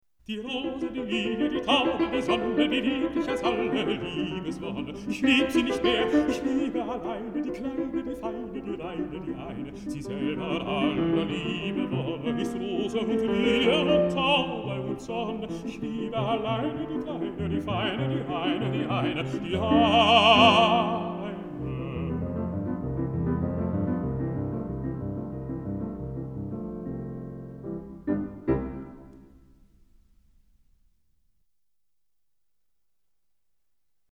Música vocal
Música clásica